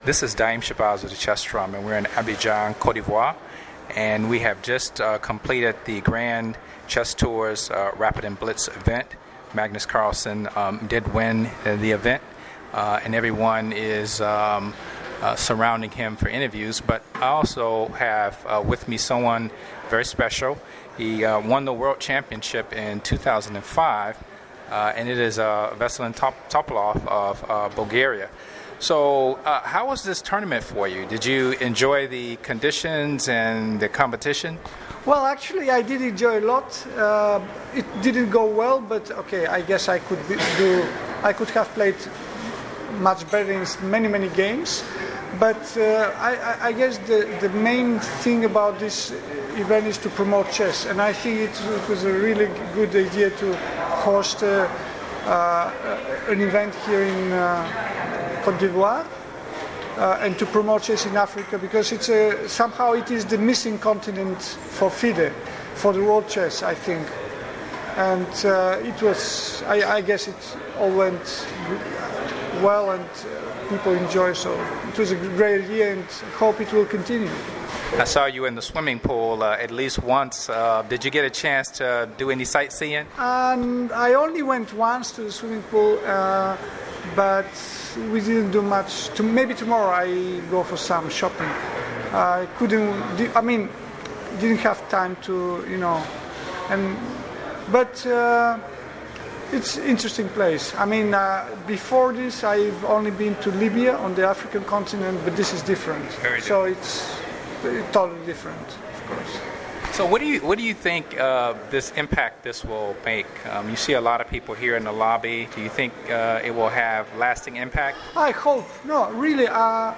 I later interviewed him and he covered the topic in more detail.
The evening ended with a short closing ceremony and lots of selfies.
It was a refreshing interview and he even complimented me on my outfit.